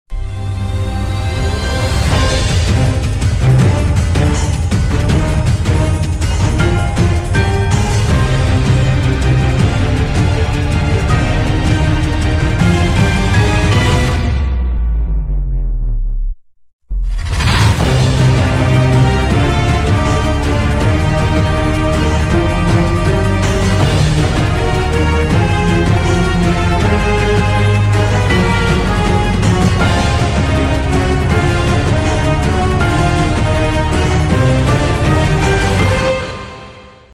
OFFICIAL Full Short Theme Song